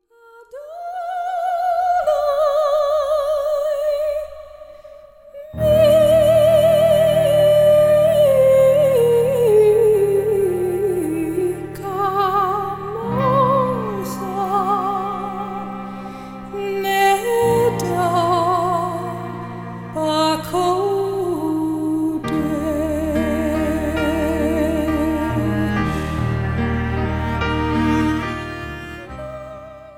CJM (Contemporary Jewish Music)